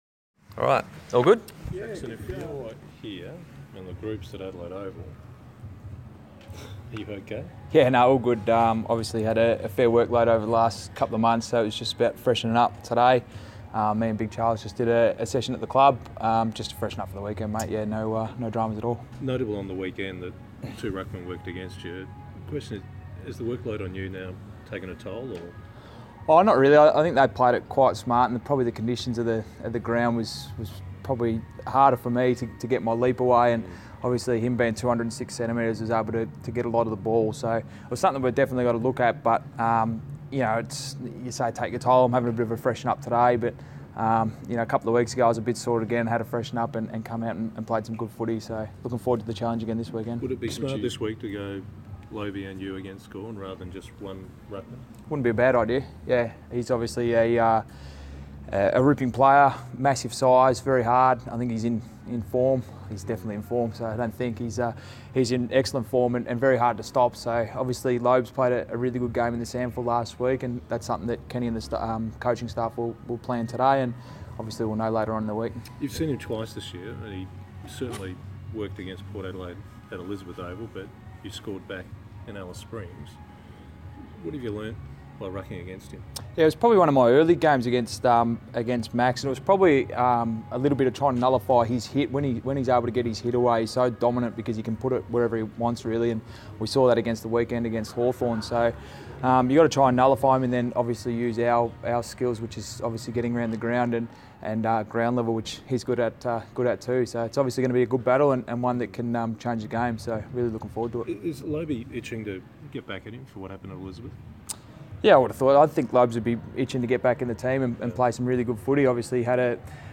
Jackson Trengove press conference - 10 August 2016